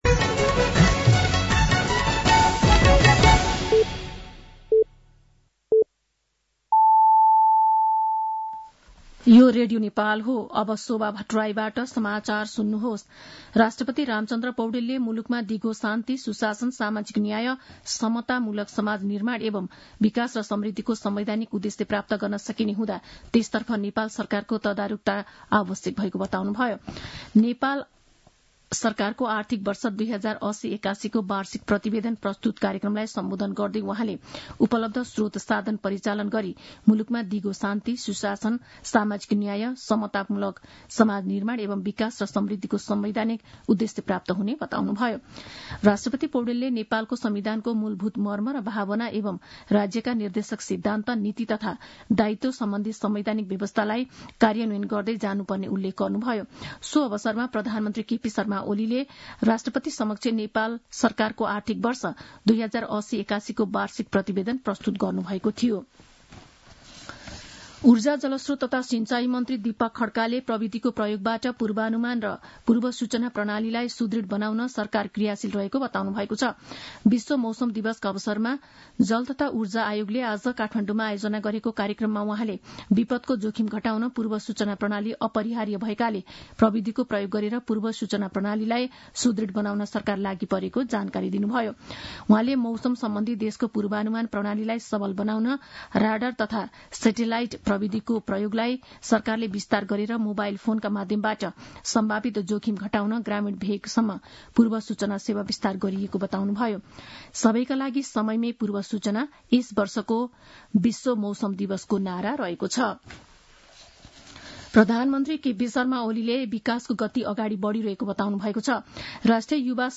साँझ ५ बजेको नेपाली समाचार : १० चैत , २०८१
5-pm-news-7.mp3